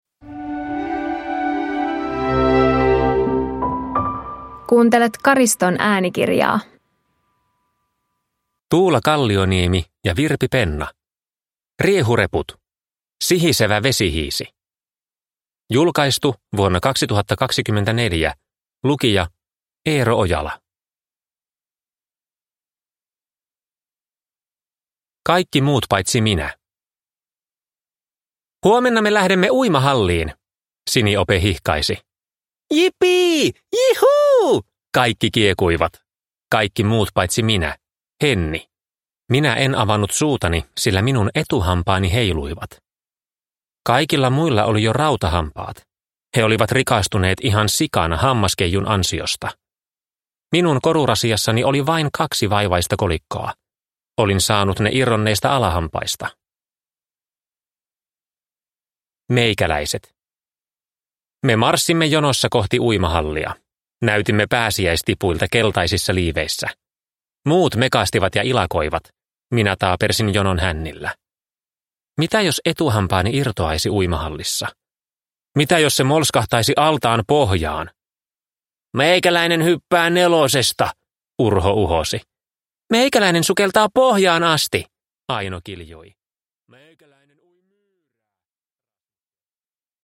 Riehureput – Sihisevä vesihiisi – Ljudbok